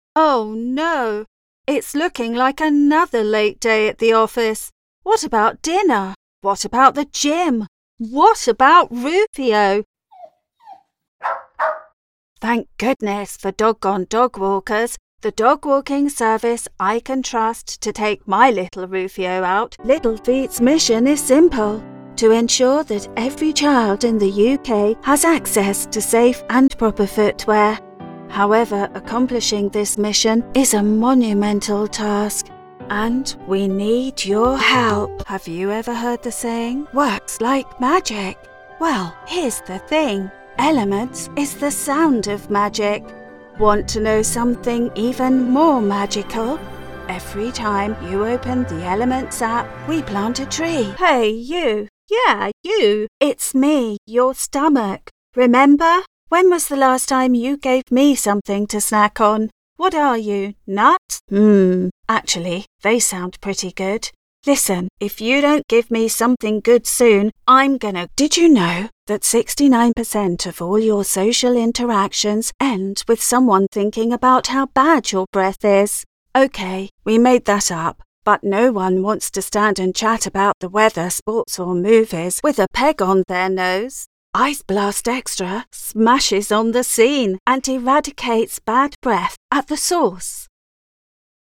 British Female Voice Over Talent RP
Naturally smooth, warm and relatable - British Neutral and RP